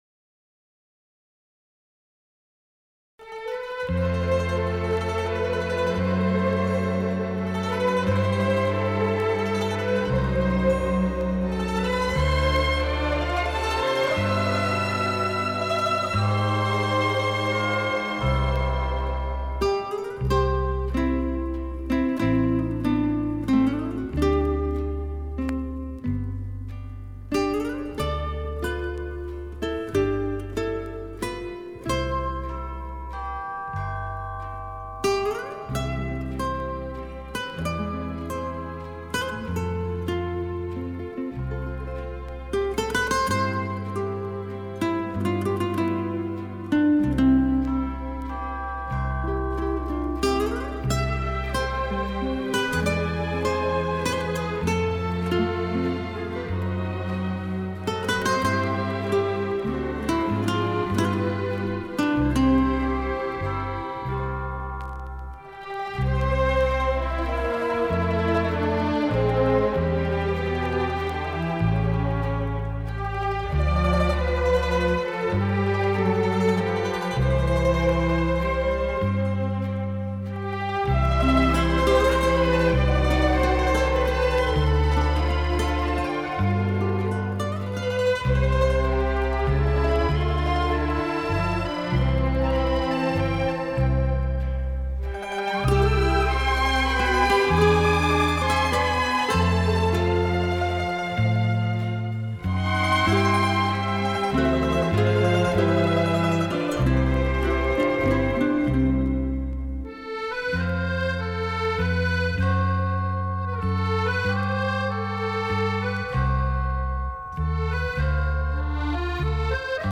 Японские гитары и оркестр